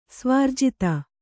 ♪ svārjita